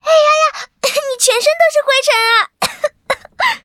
文件 文件历史 文件用途 全域文件用途 Fifi_tk_02.ogg （Ogg Vorbis声音文件，长度3.6秒，100 kbps，文件大小：44 KB） 源地址:游戏语音 文件历史 点击某个日期/时间查看对应时刻的文件。